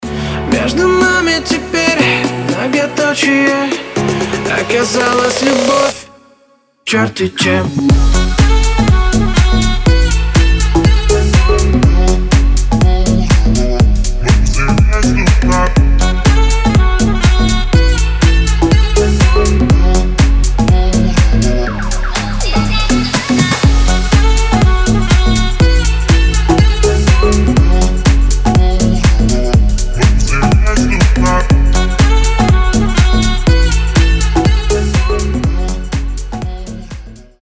• Качество: 320, Stereo
поп
мужской голос
dance
Electronic